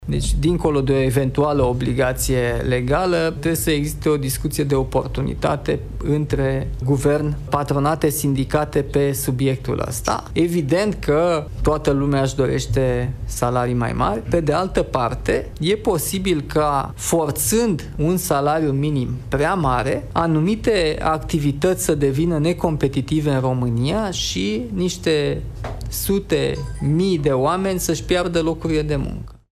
Declarația a fost făcută, în weekend, la Iași unde Nicușor Dan a avut o întâlnire cu oamenii de afaceri.
25oct-14-Nicusor-dan-despre-salariul-minim.mp3